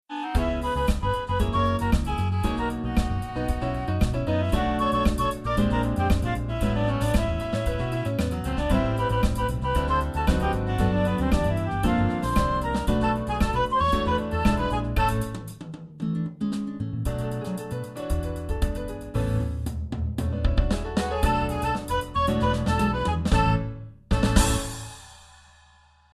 Jazz Example: Samba